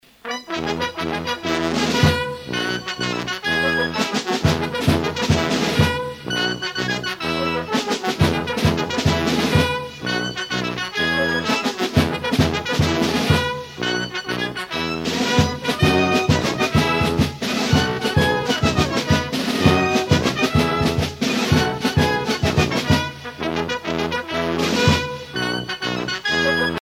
circonstance : carnaval, mardi-gras ;
Pièce musicale éditée